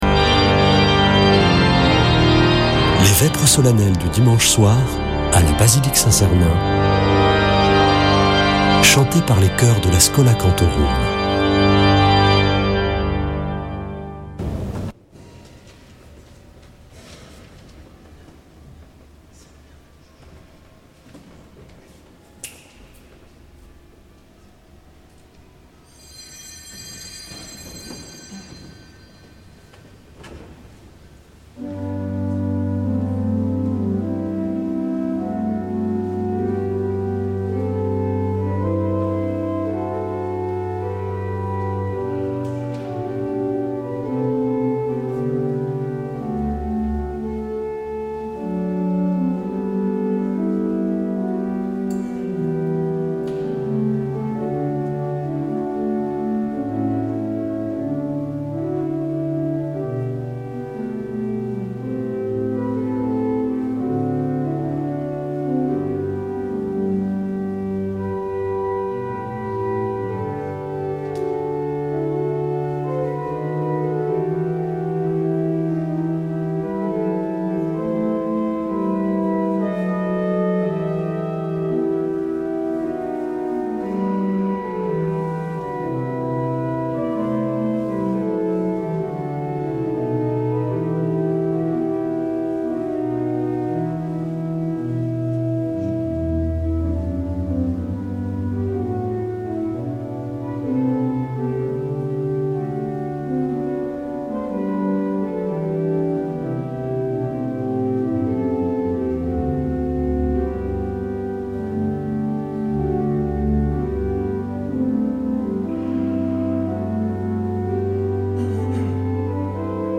Vêpres de Saint Sernin du 27 avr.
Schola Saint Sernin Chanteurs